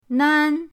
nan1.mp3